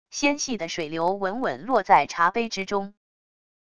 纤细的水流稳稳落在茶杯之中wav音频